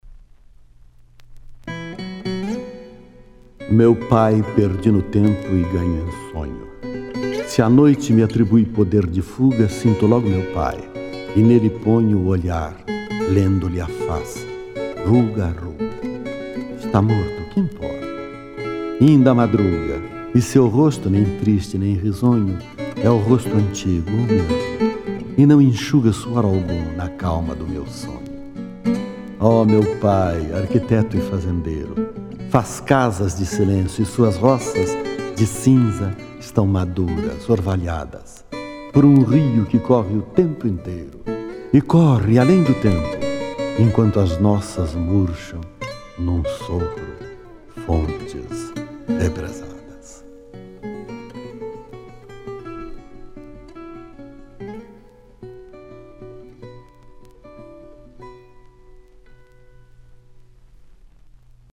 Carlos Drummond de Andrade interpretado por Lima Duarte - Músicas: Roberto Corrêa